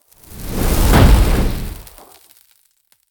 spell-impact-4.ogg